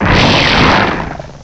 Add all new cries
cry_not_barbaracle.aif